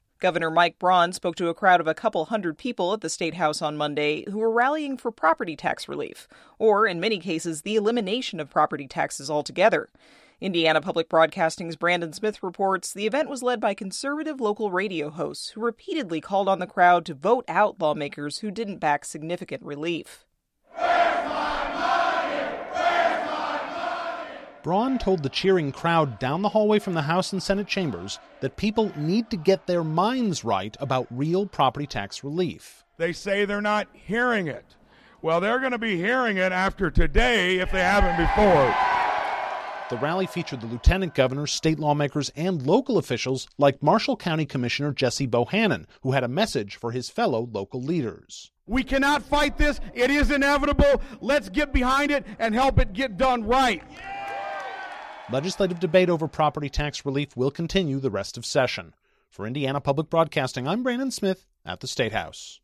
Gov. Mike Braun spoke to a crowd of a couple hundred people at the Statehouse Monday who were rallying for property tax relief — or, in many cases, the elimination of property taxes altogether.
The rally specifically called out Senate President Pro Tem Rodric Bray (R-Martinsville) and House Ways and Means Chair Rep. Jeff Thompson (R-Lizton), with the crowd chanting “primary, primary, primary.”
property-tax-statehouse-rally.mp3